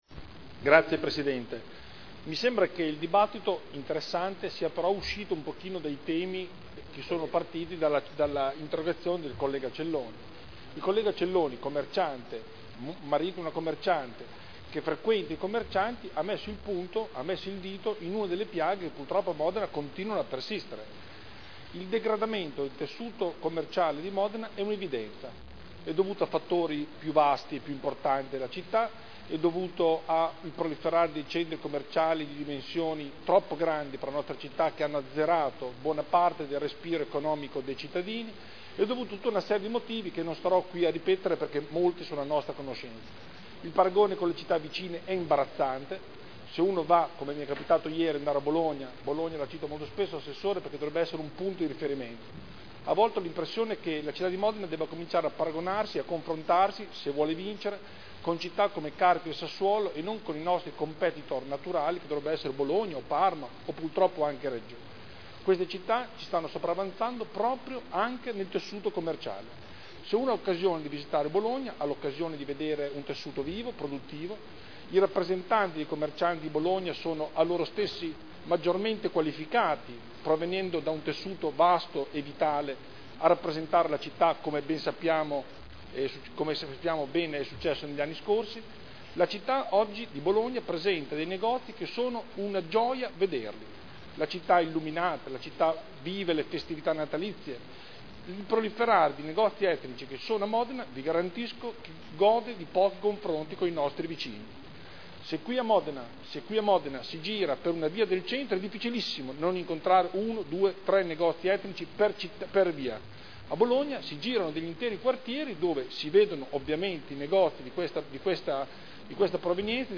Seduta del 6/12/2010. Dibattito.